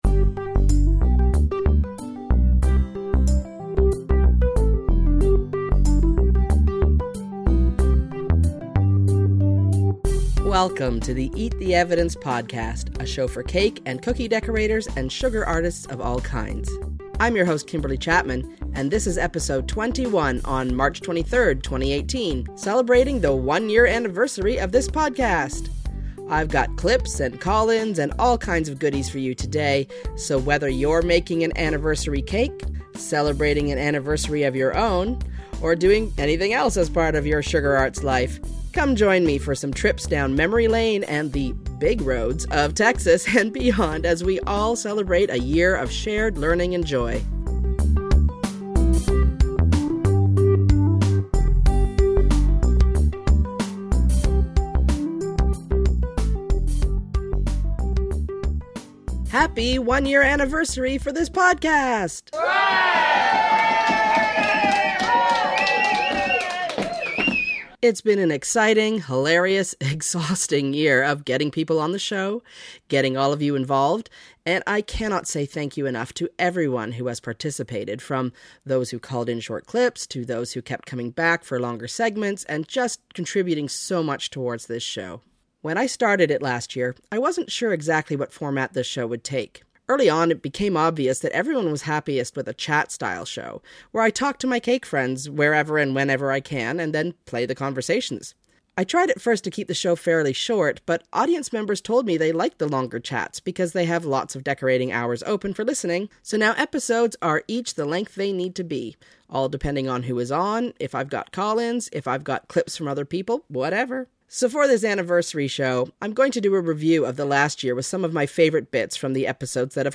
Celebrating the past year’s worth of episodes with clips and call-ins
Music/Sound Credits The following songs and sound clips were used in this episode of Eat the Evidence.